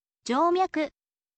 joumyaku